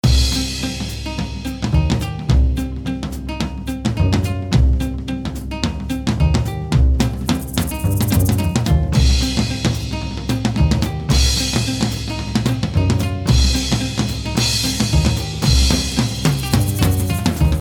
Explosion1.mp3